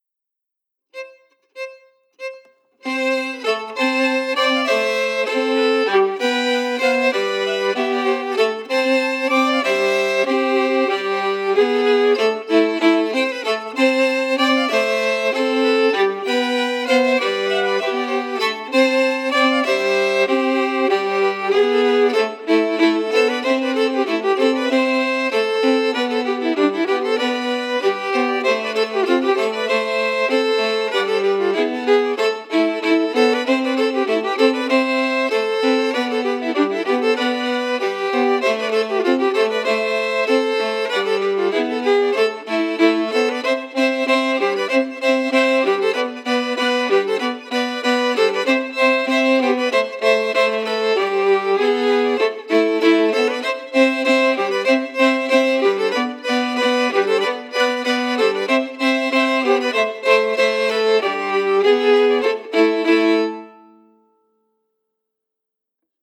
Key: Am
Form: Reel
Harmony emphasis
Red-House-audio-harmonies-emphasized.mp3